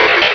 sound / direct_sound_samples / cries / mudkip.aif